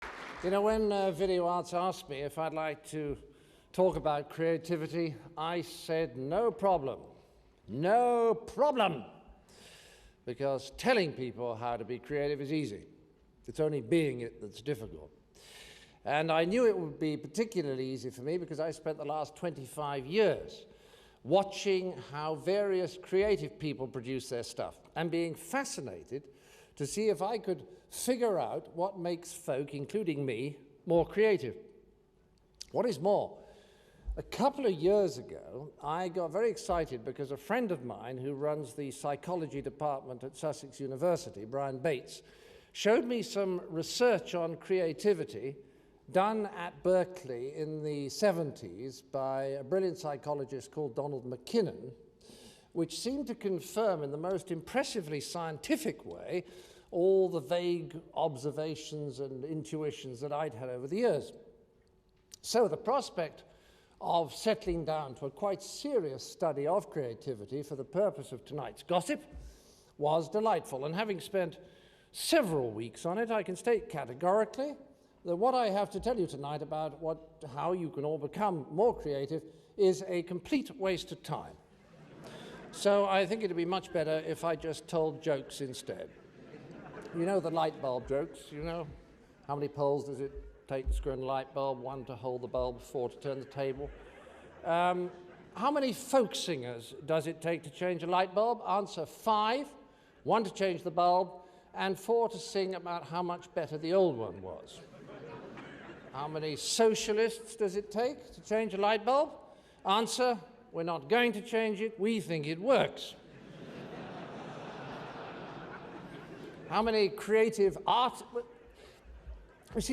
grand-theft-arena/John Cleese's Legendary 1991 Speech About Creativity [nvKeu46jgwo].opus at 60b024ecc66fb22ebb67e382c41aa15830d40af7